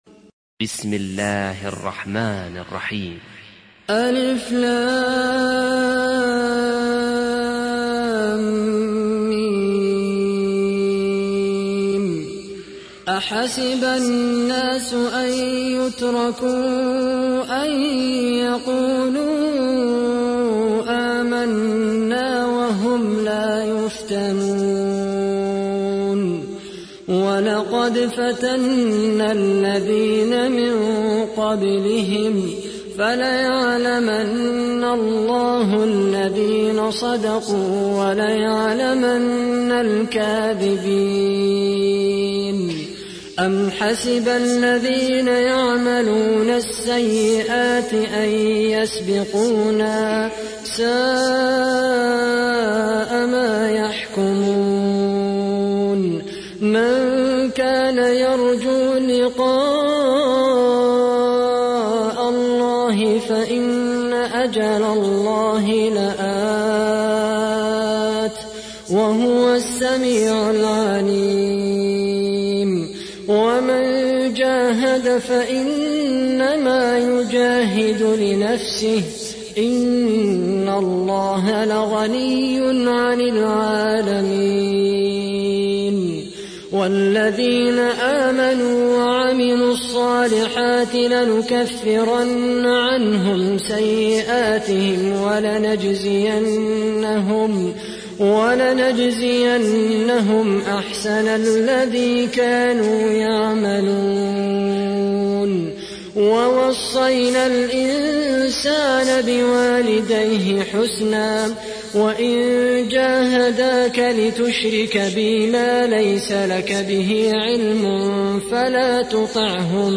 تحميل : 29. سورة العنكبوت / القارئ خالد القحطاني / القرآن الكريم / موقع يا حسين